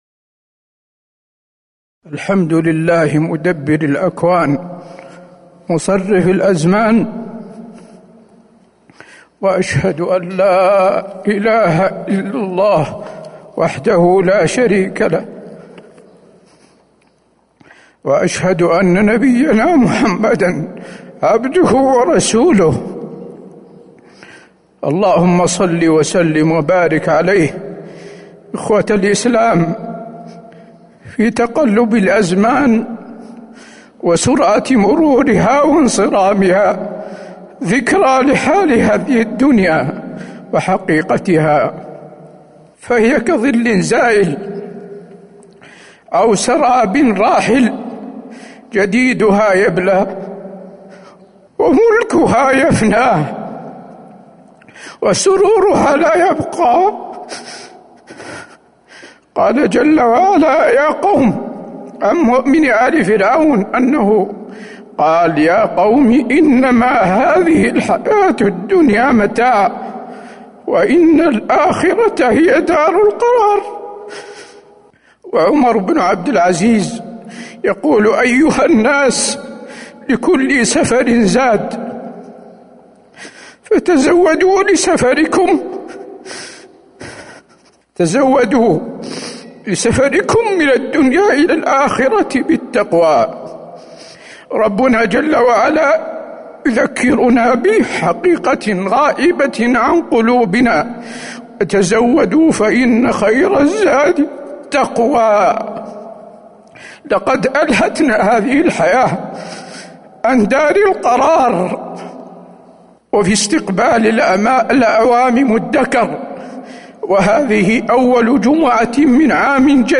تاريخ النشر ٥ محرم ١٤٤٣ هـ المكان: المسجد النبوي الشيخ: فضيلة الشيخ د. حسين بن عبدالعزيز آل الشيخ فضيلة الشيخ د. حسين بن عبدالعزيز آل الشيخ حقيقة الدنيا